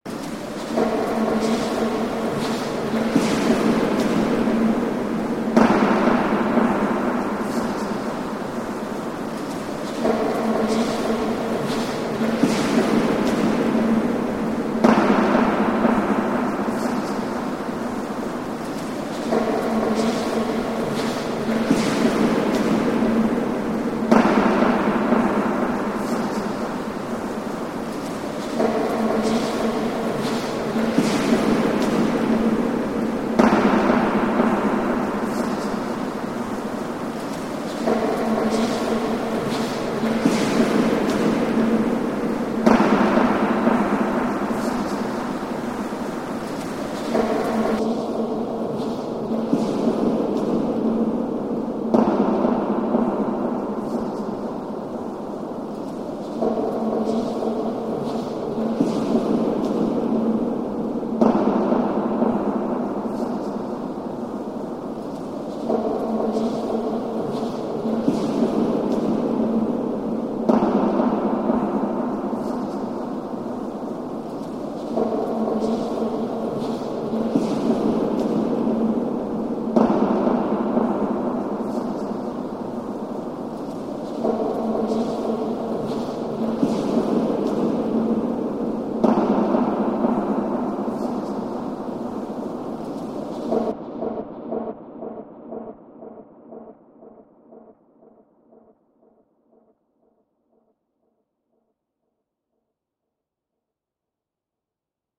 sound from the Museum MHKA Antwerpen, Belgium